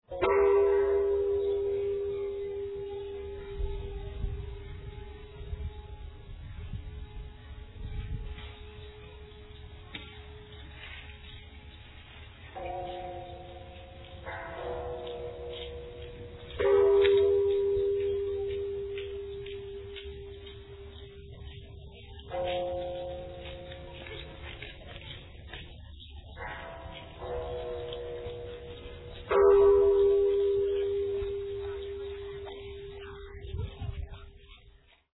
Temple Bells - 1:20